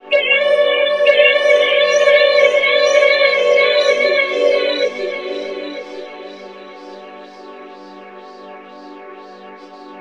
SCREAMVOC -R.wav